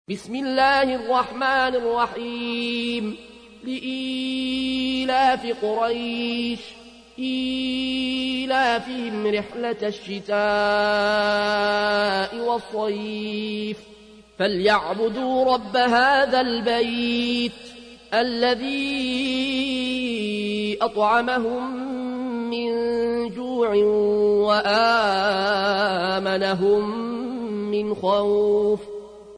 تحميل : 106. سورة قريش / القارئ العيون الكوشي / القرآن الكريم / موقع يا حسين